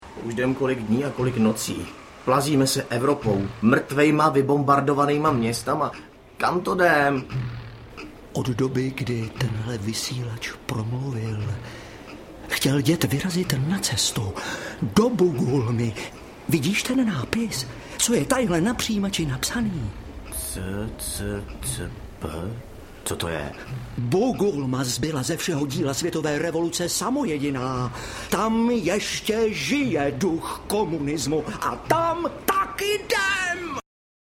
Cesta do Bugulmy audiokniha
Ukázka z knihy